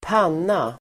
Uttal: [²p'an:a]